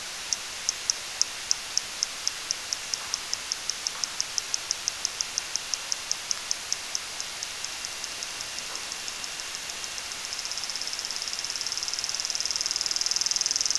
Their echolocation clicks are out of our hearing range, being at about 130 to 150 kHz.
When they find prey the pattern of clicks changes from slower searching rates to higher rates of clicks (shorter intervals) when approaching the fish (picture 4).
The highest rates (>600 clicks/s) occur just before capture. You can hear a facsimile of picture 4 in audio 1 (reduced by 22.7 times so we can hear the echolocation signals).
LISTEN: Porpoise Hunting Fish